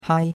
hai1.mp3